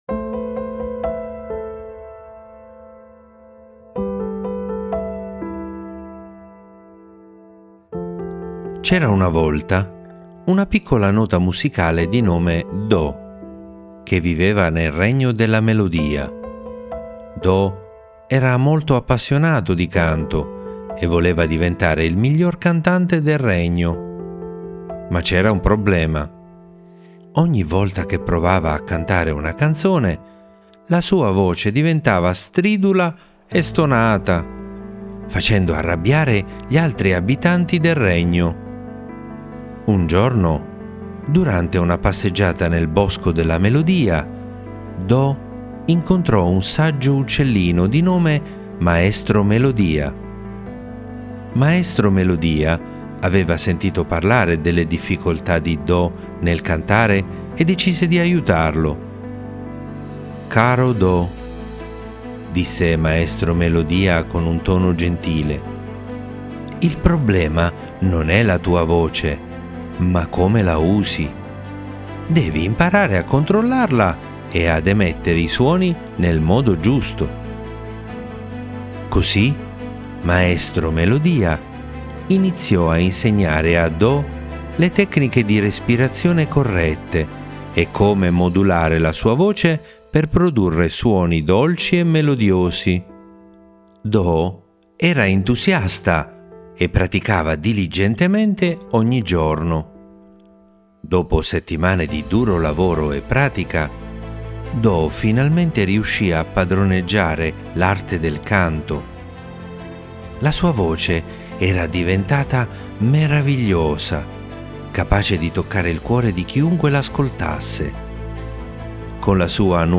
Leggiamo ed ascoltiamo la favola de "il do di petto"